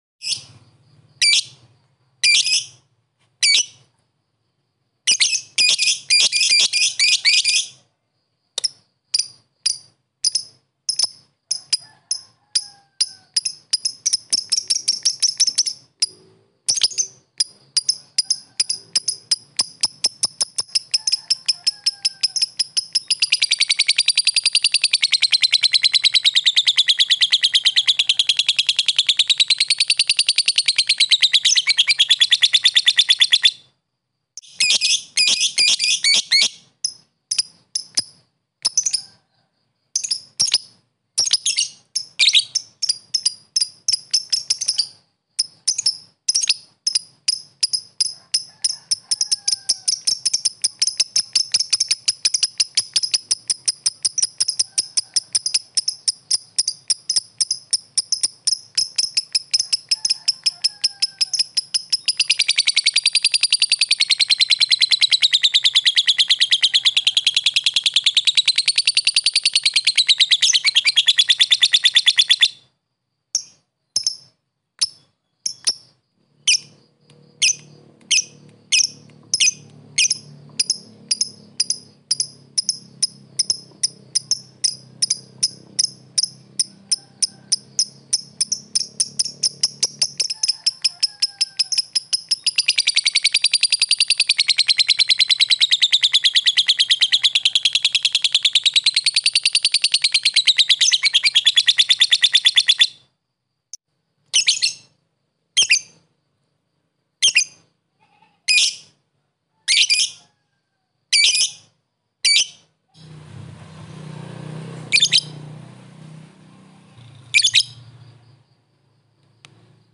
Download mp3 suara lovebird ngetik ngekek full durasi Stafaband, cocok buat masteran lovebird fighter biar makin gacor.
Suara Lovebird Ngetik Ngekek
Tag: suara burung hias suara burung Lovebird suara kicau burung
suara-burung-lovebird-ngetik-ngekek-id-www_tiengdong_com.mp3